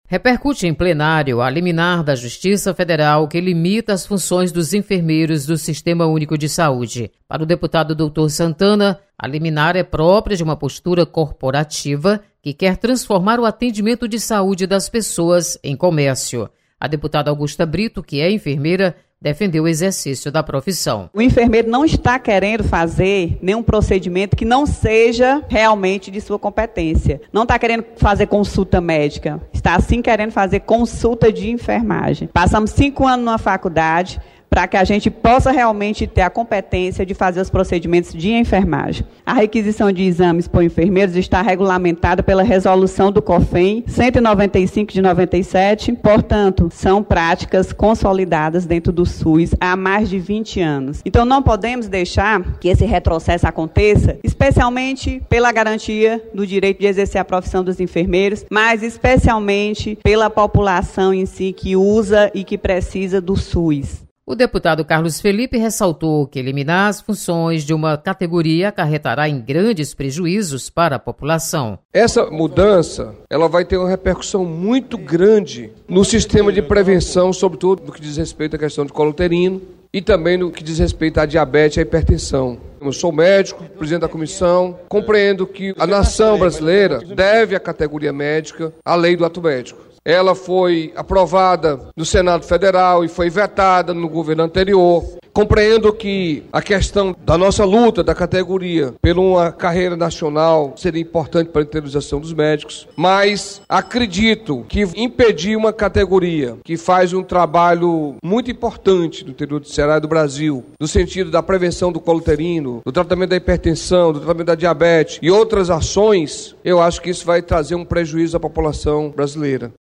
Você está aqui: Início Comunicação Rádio FM Assembleia Notícias Enfermeiros